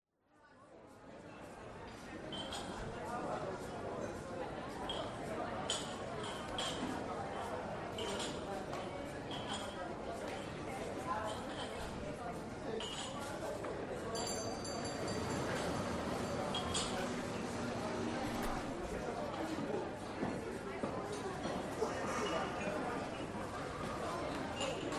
Coffee Shop
Coffee Shop is a free ambient sound effect available for download in MP3 format.
284_coffee_shop.mp3